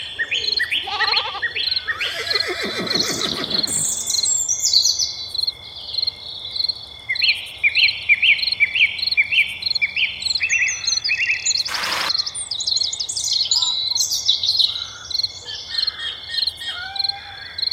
Ambiance Cour de ferme (Broadcast) – Le Studio JeeeP Prod
Bruits d’ambiance dans une cour de ferme